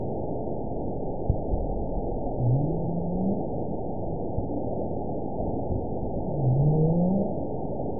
event 916208 date 12/27/22 time 07:27:43 GMT (2 years, 11 months ago) score 9.34 location INACTIVE detected by nrw target species NRW annotations +NRW Spectrogram: Frequency (kHz) vs. Time (s) audio not available .wav